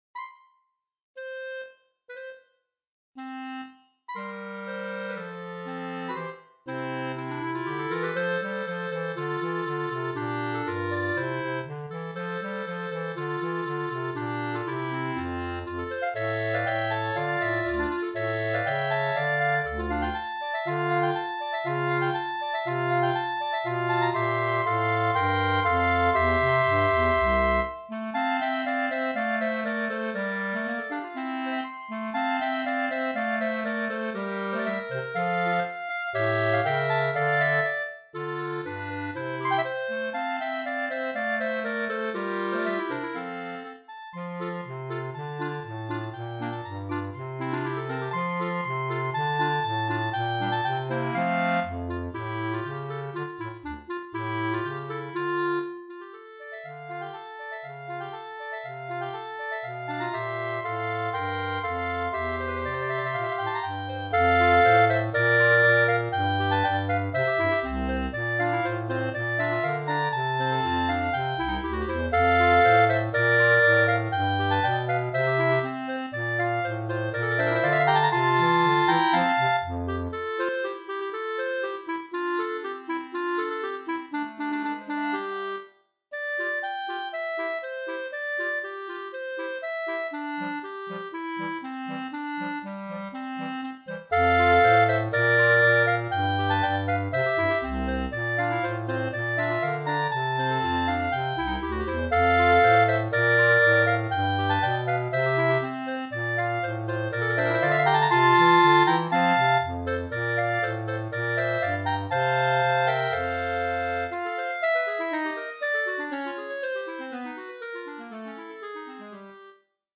B♭ Clarinet 1 B♭ Clarinet 2 B♭ Clarinet 3 Bass Clarinet
单簧管四重奏
请以进行曲的速度，欢快地演奏这首乐曲吧！